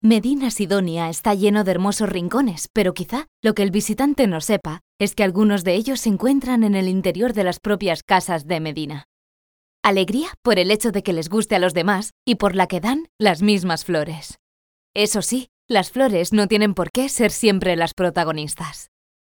Locutora, home studio, actriz
Sprechprobe: Industrie (Muttersprache):
Voice over, home studio, actress